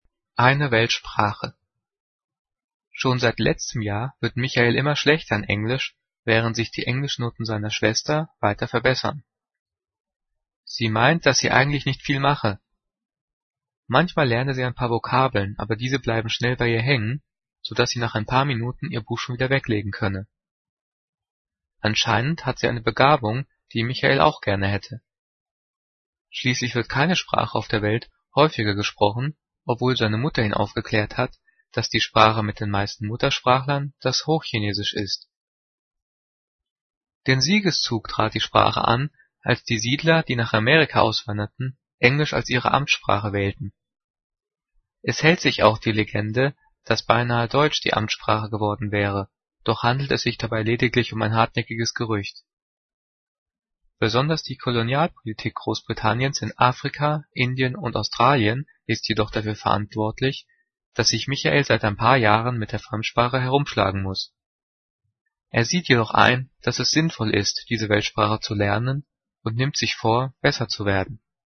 Diktat: "Eine Weltsprache" - 7./8. Klasse - Zeichensetzung
Gelesen:
gelesen-eine-weltsprache.mp3